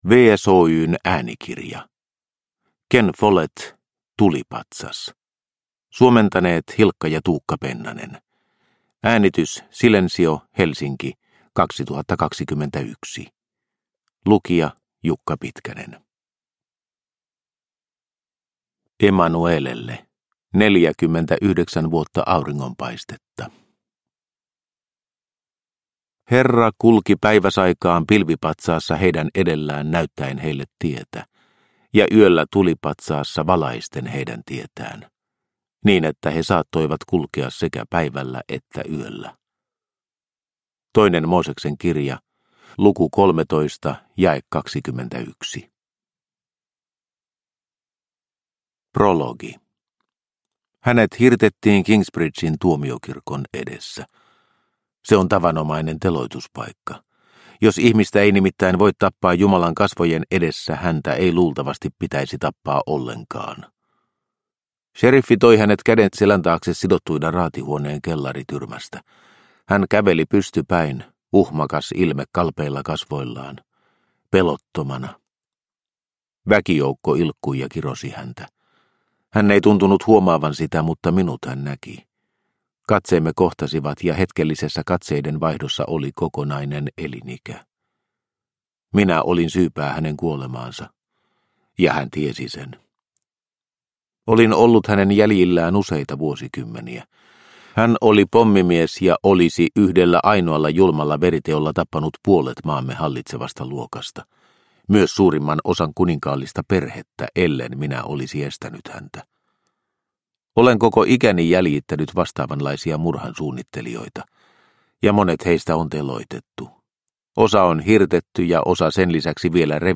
Tulipatsas – Ljudbok – Laddas ner